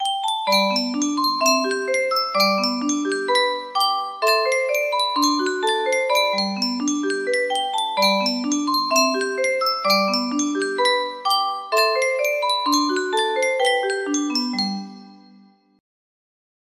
Yunsheng Music Box - Home Sweet Home 1405 music box melody
Full range 60